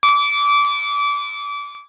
Alert16.wav